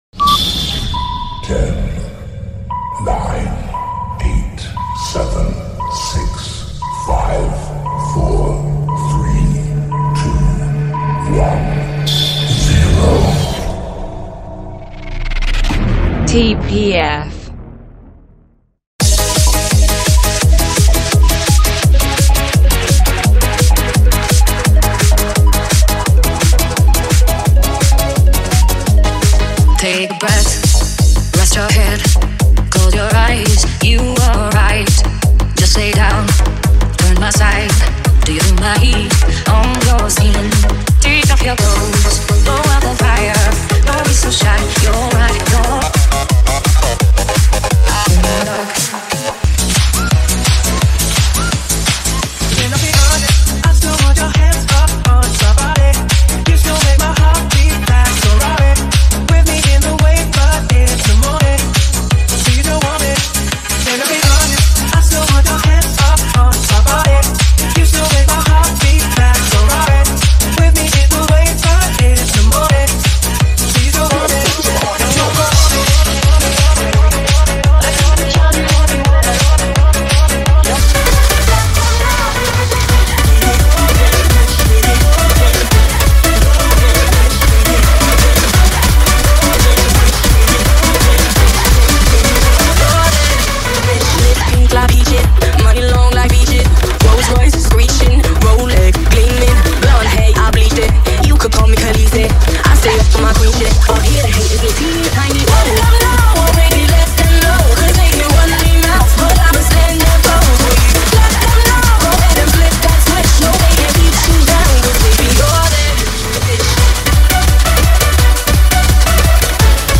(32 count phrased) 170 BPM
Tempo:      170 BPM